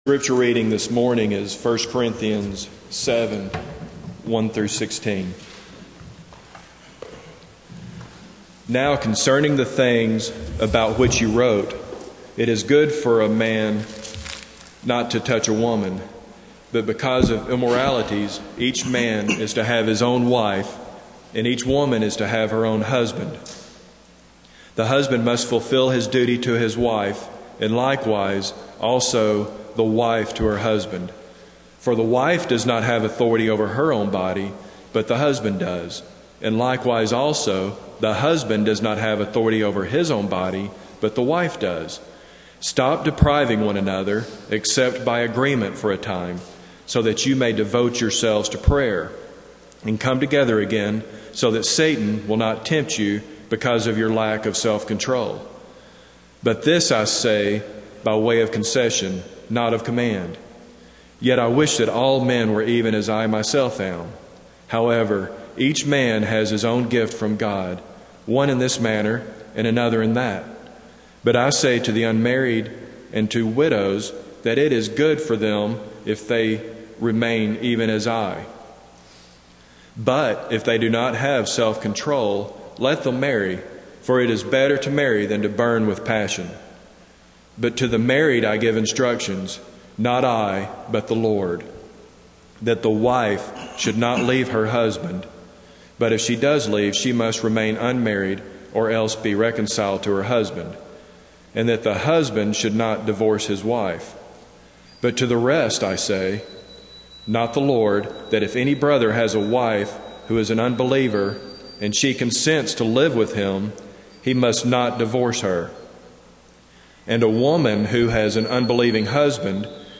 Passage: 1 Corinthians 7:1-15 Service Type: Sunday Morning